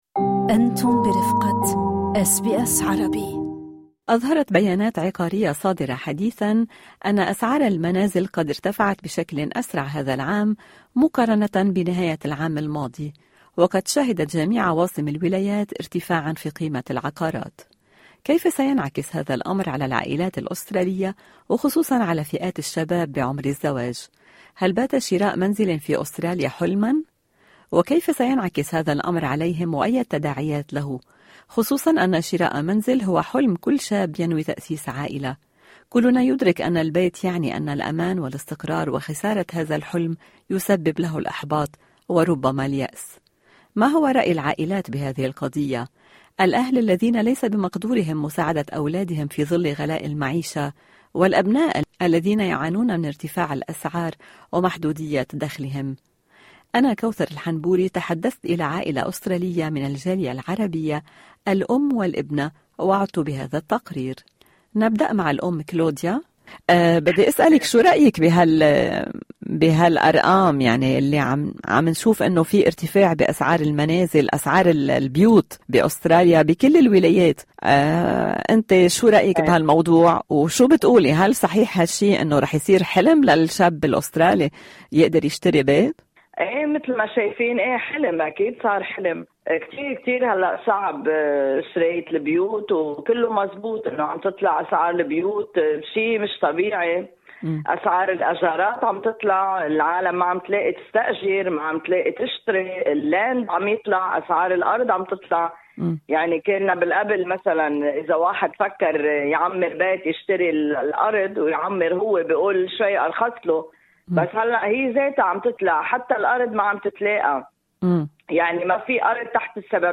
تحدثت إلى عائلة أسترالية من الجالية العربية وحديث من الأم والابنة وعادت بالتقرير في الملف الصوتي أعلاه.